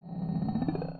charge.wav